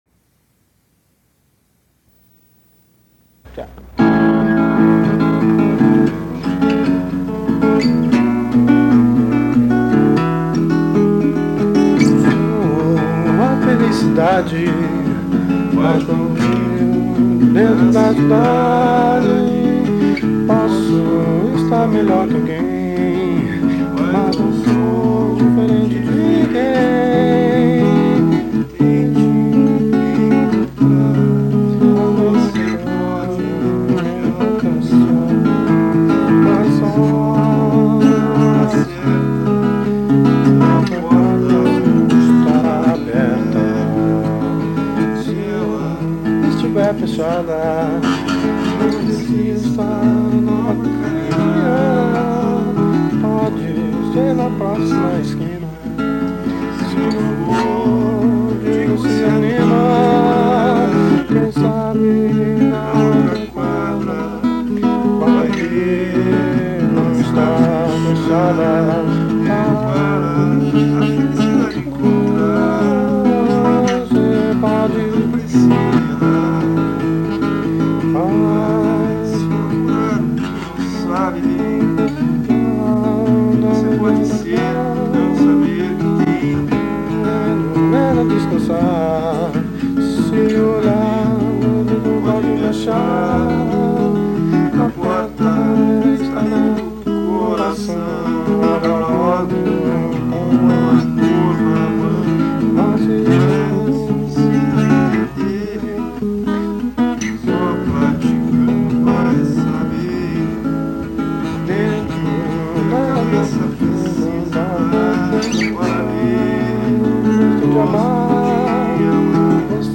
Musicas gravadas no momento de criação, realizadas no improviso, sem ensaio, sem estúdio ( faltam mais instrumentos em arranjos e ensaio, outras Letras melhores e mais importantes estão para terem acompanhamento de violão e ritmo, ou menos, ou mais, para virarem Novos Protótipos ou Esboços como base para Gravação )...